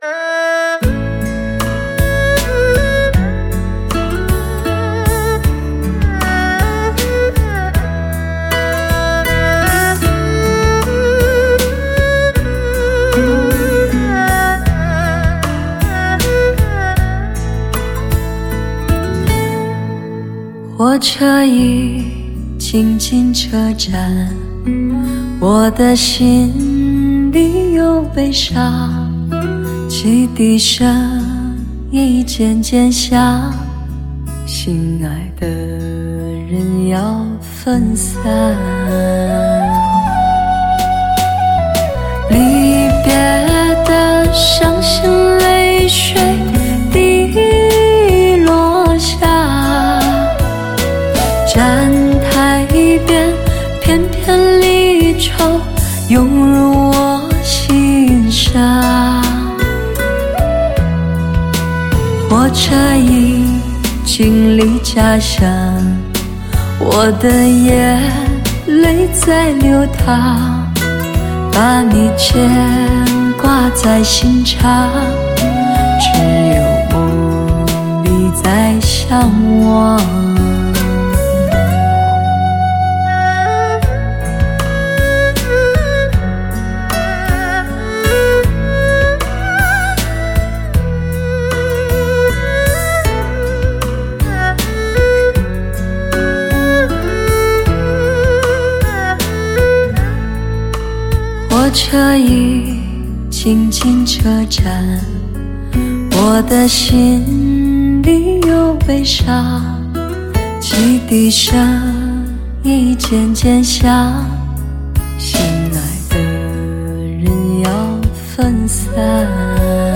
Female Audiophile
至完美+高科技唯一极品监听蓝光CD+德国老虎鱼母盘直刻发烧录制处理